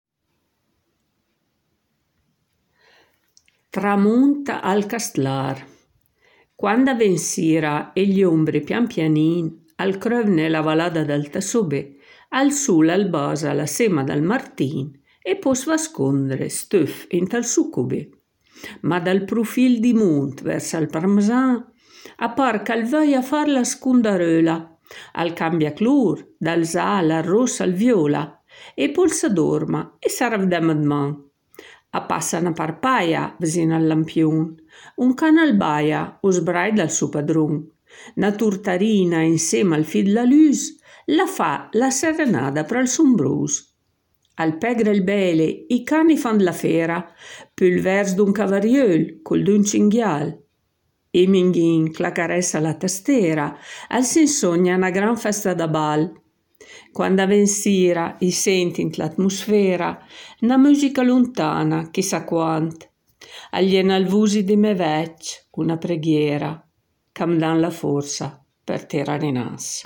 La poesia Tramûnt al Castlâr è letta